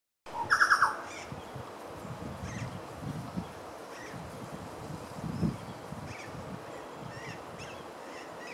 Chucao Tapaculo (Scelorchilus rubecula)
Life Stage: Adult
Location or protected area: Parque Nacional Lanín
Condition: Wild
Certainty: Observed, Recorded vocal
Chucao.mp3